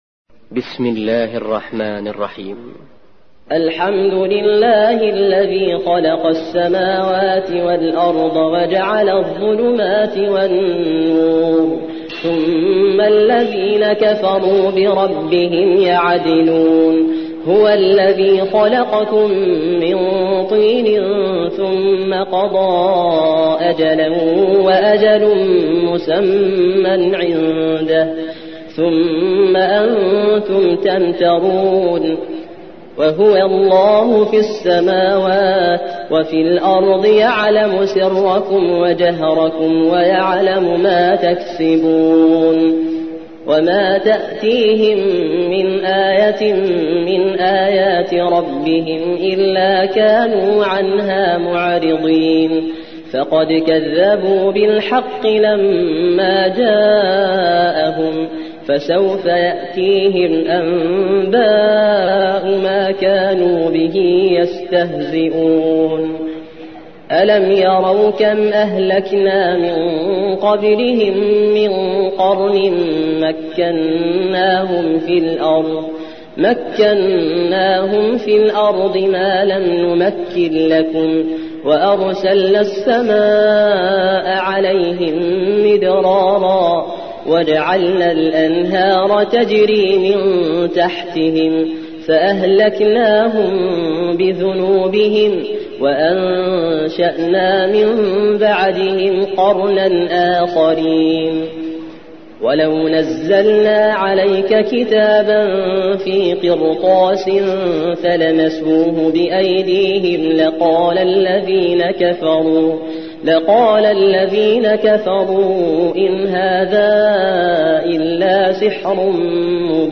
6. سورة الأنعام / القارئ